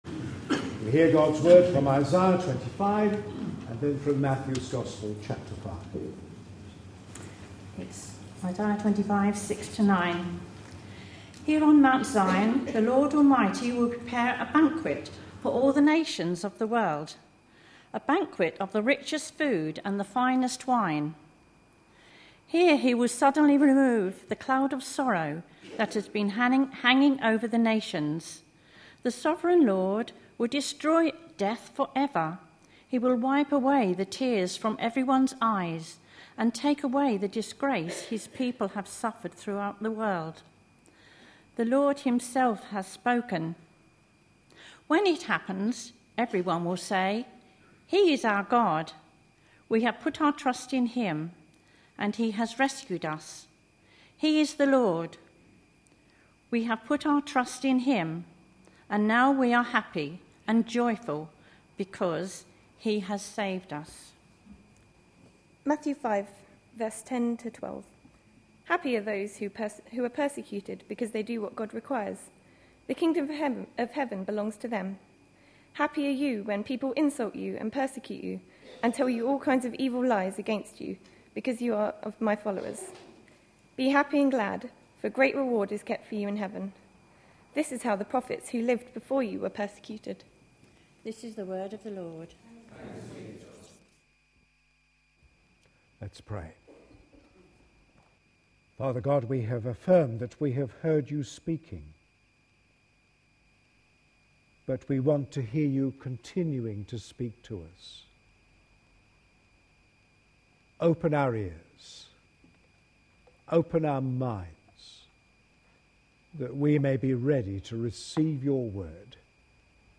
A sermon preached on 27th November, 2011, as part of our The Beatitudes. series.
(This service was the first Sunday in Advent.)